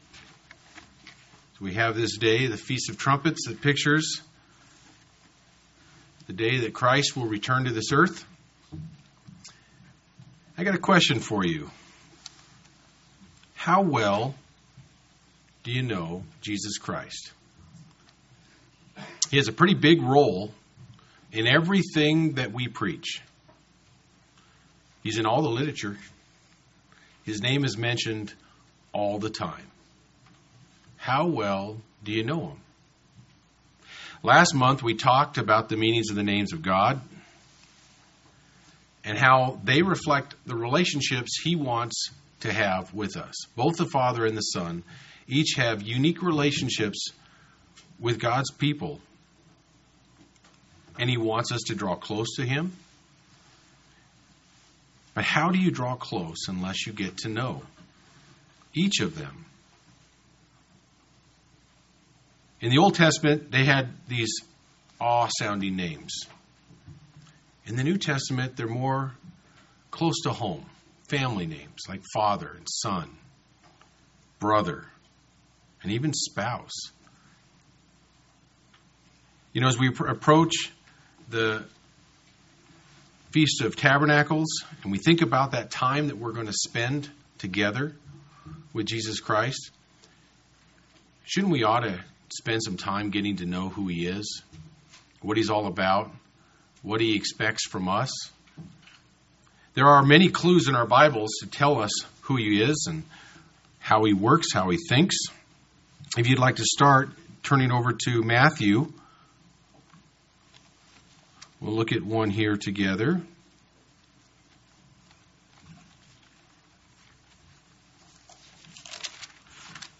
This sermon asks how well you know Jesus Christ and explores the seven “I am” statements made by Christ.
Given in Medford, OR Central Oregon